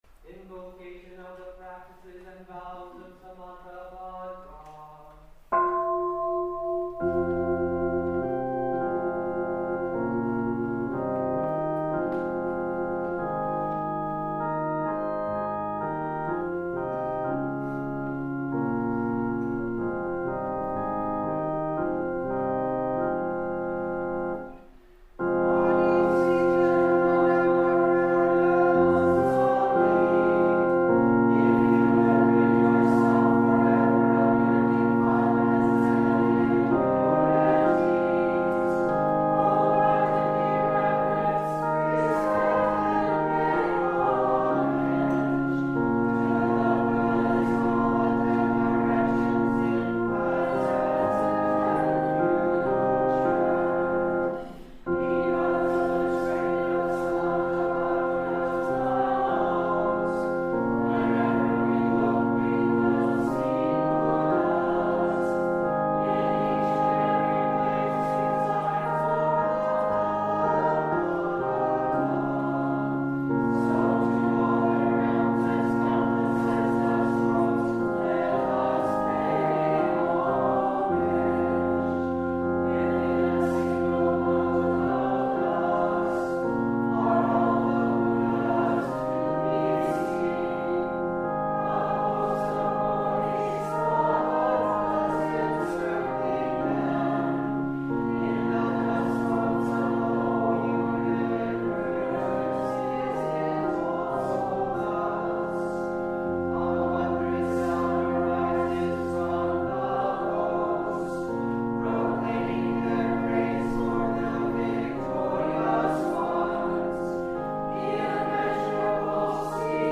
Every Wednesday evening at 7 o’clock, the Priory has the group chanting of The Scripture on the Practices and Vows of Samantabhadra.
The Scripture on the Practices and Vows of Samantabhadra(listen/download 19:21 recording of Shasta Abbey choir)